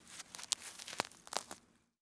SFX_Harvesting_04.wav